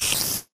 spider3.ogg